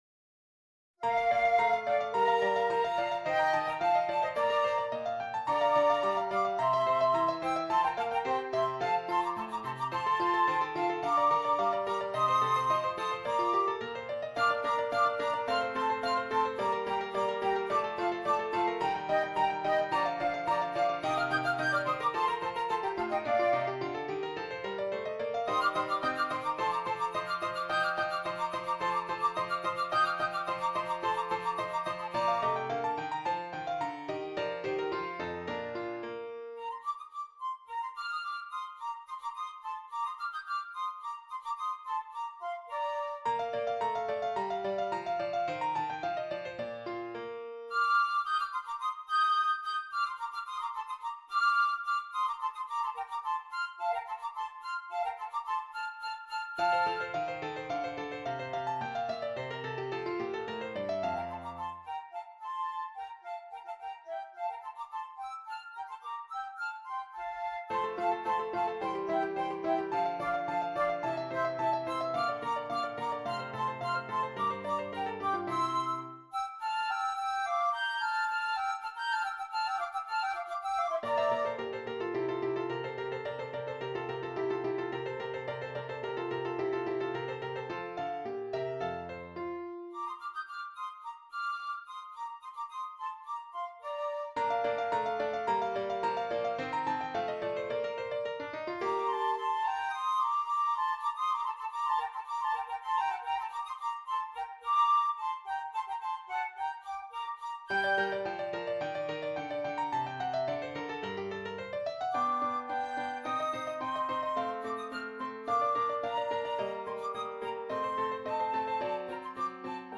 2 Flutes and Keyboard